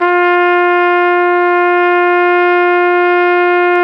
Index of /90_sSampleCDs/Roland L-CD702/VOL-2/BRS_Flugelhorn/BRS_Flugelhorn 2